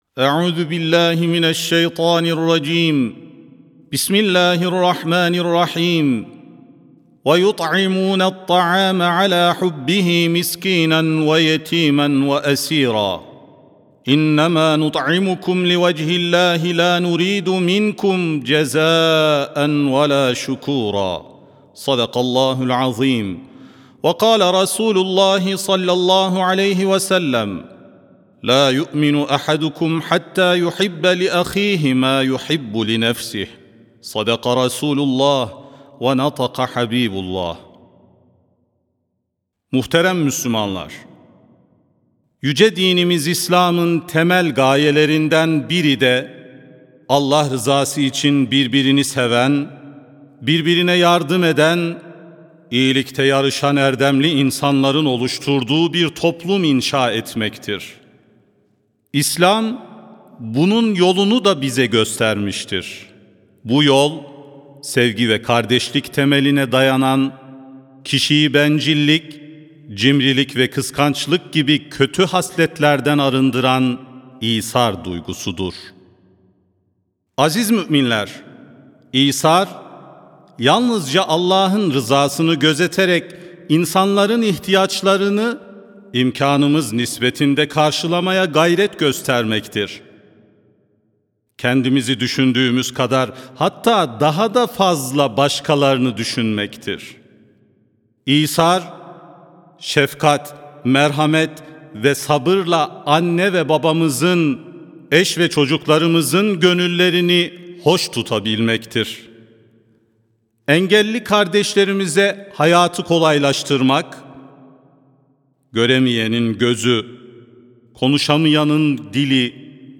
Sesli Hutbe (Fedakarlığın Zirvesi İsar)
Sesli Hutbe (Fedakarlığın Zirvesi İsar).mp3